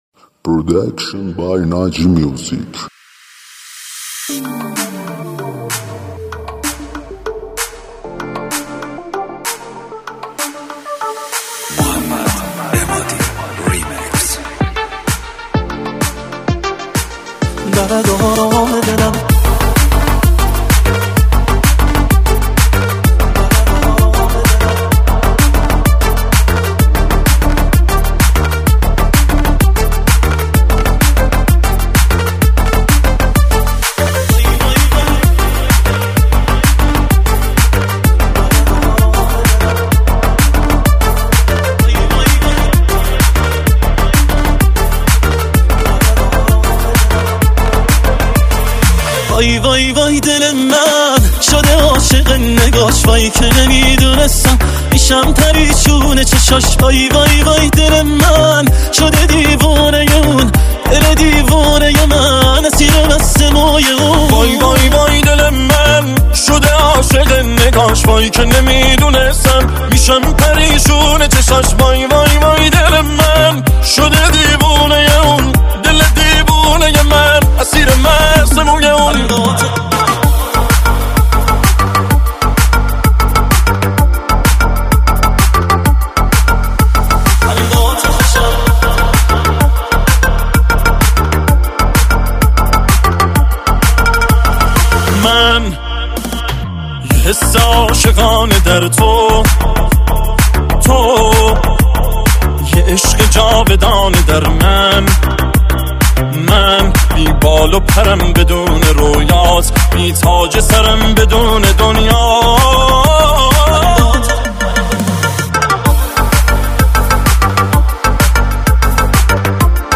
ریمیکس ارکستی بندی همراه با نی انبان
ریمیکس شاد و بندری مخصوص رقص